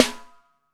808 RIM.wav